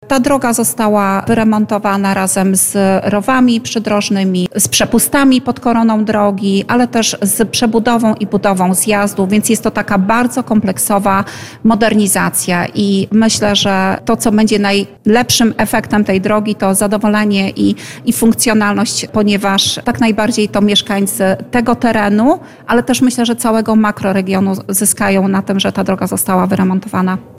Droga łączy powiat łomżyński oraz kolneński – wyjaśniła wicestarosta łomżyński i wiceprezes Związku Powiatowego-Gminnego „Łomżyńskie Forum Samorządowe” Anna Gawrych.